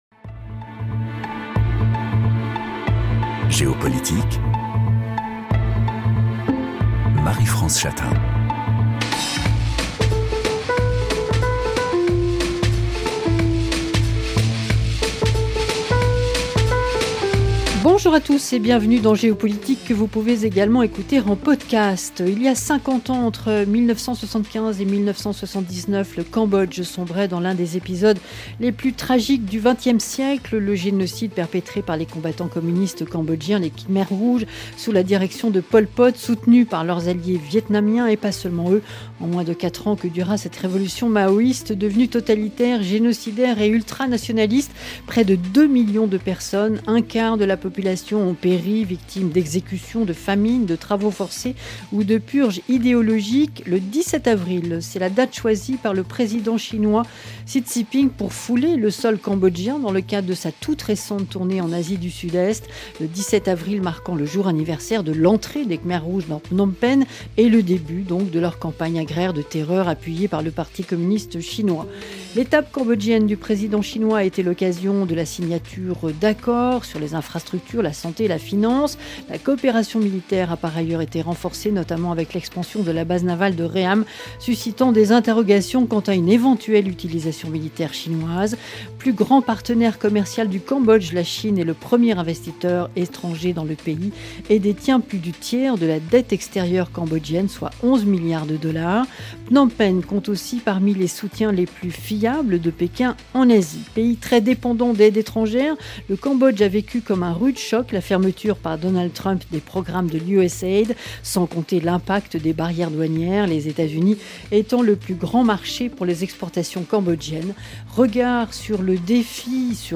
Les invités de Géopolitique confrontent leurs regards sur un sujet d’actualité internationale.